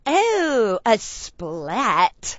oh_a_split.wav